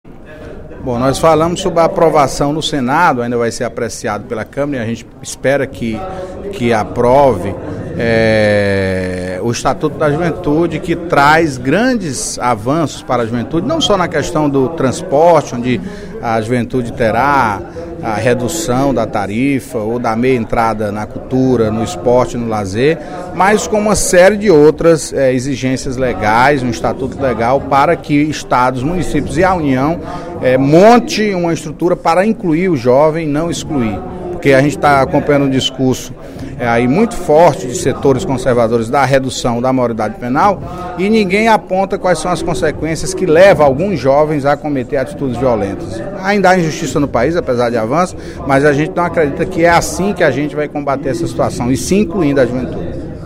O deputado Antonio Carlos (PT) encerrou o primeiro expediente desta quinta-feira (18/04) da Assembleia Legislativa comentando a aprovação do Estatuto da Juventude, na última terça-feira (16), pelo Senado Federal.